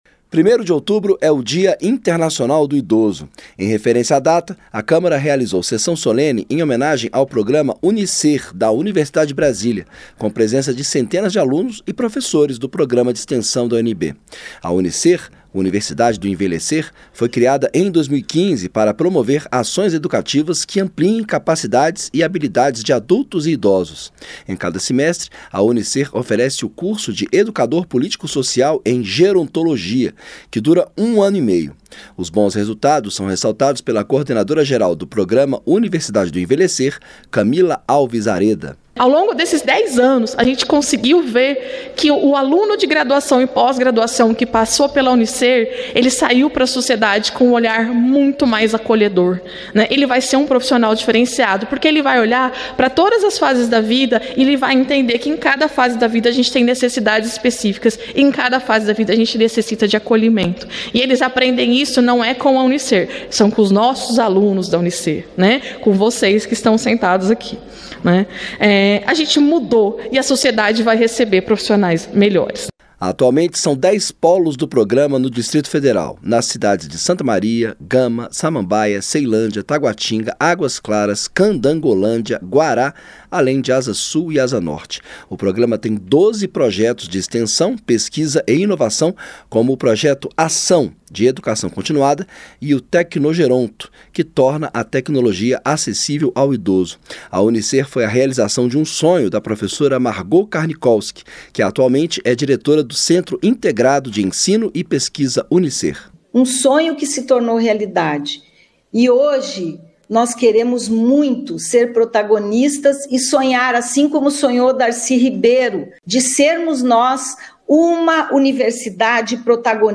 Sessão solene acontece no Dia Internacional do Idoso